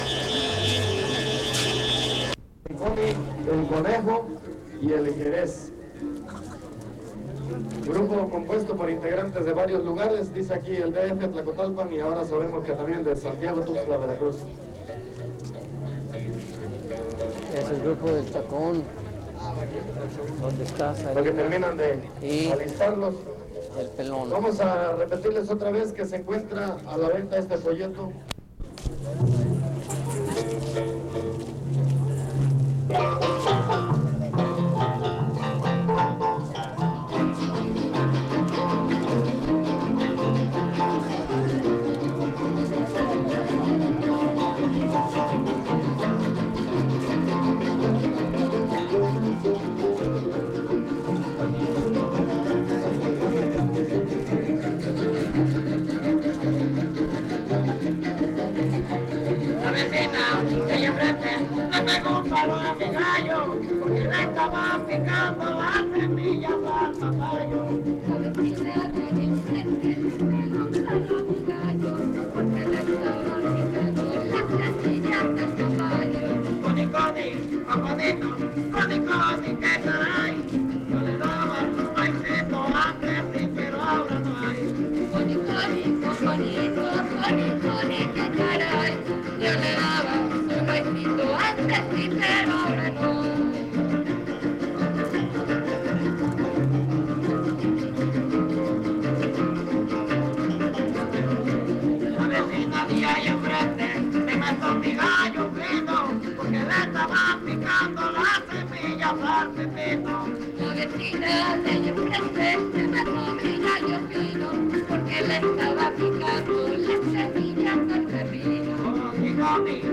Fiesta de la Candelaria